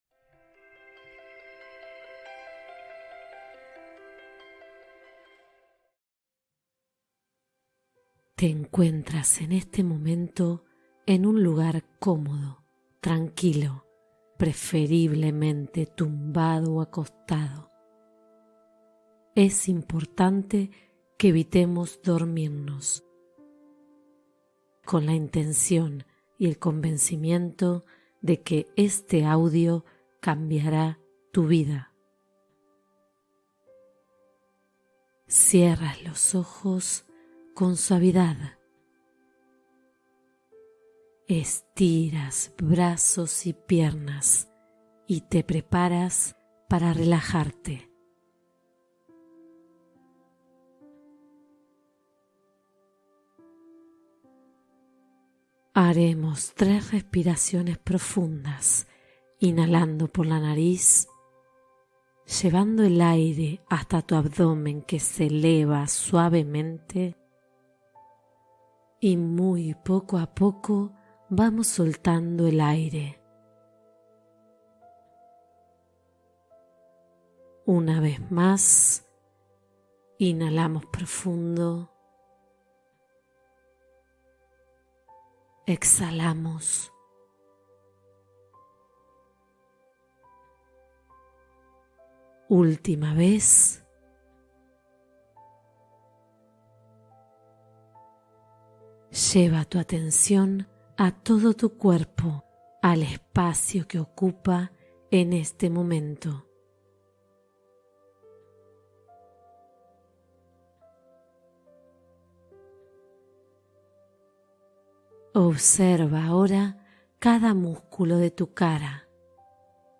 Eleva tu vibración energética con esta meditación mindfulness guiada